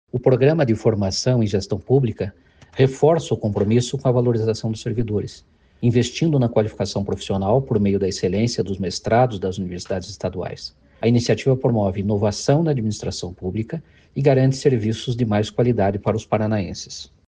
Sonora do secretário estadual da Ciência, Tecnologia e Ensino Superior em exercício, Jamil Abdanur Júnior, sobre o programa de qualificação de servidores